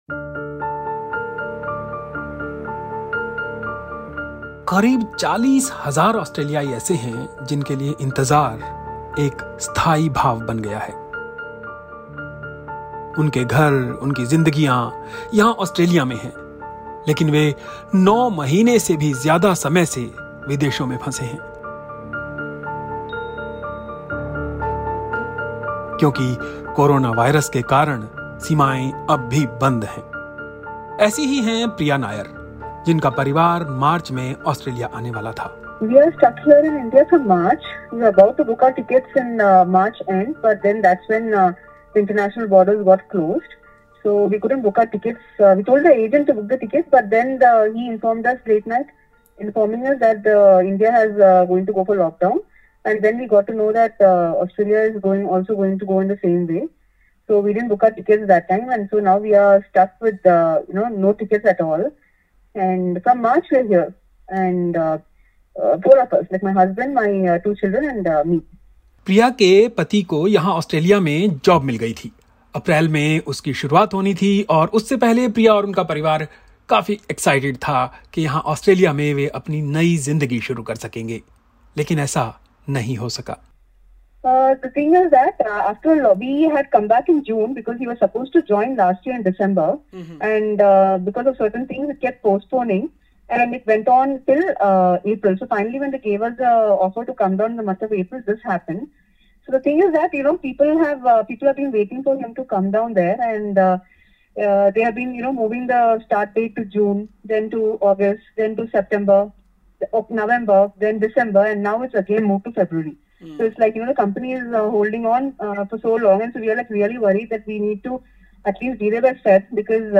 SBS Hindi View Podcast Series